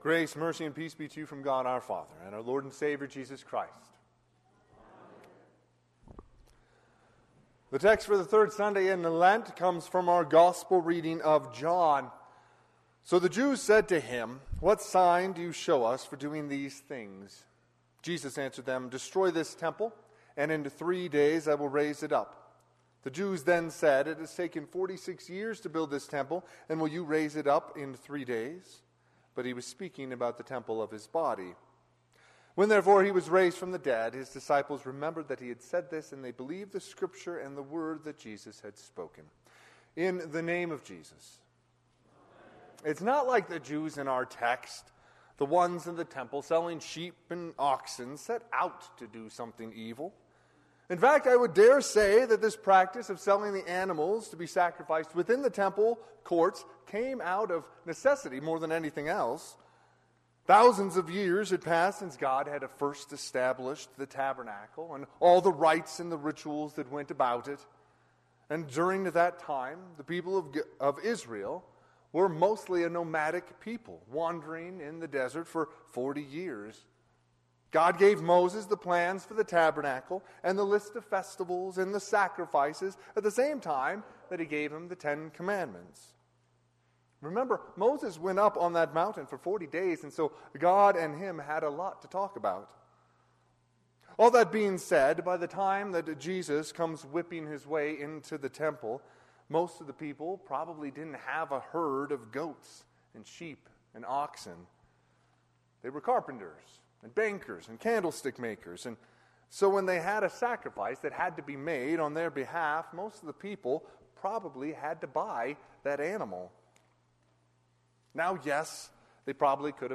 Sermon - 3/3/2024 - Wheat Ridge Lutheran Church, Wheat Ridge, Colorado
Sermon_Mar3_2024.mp3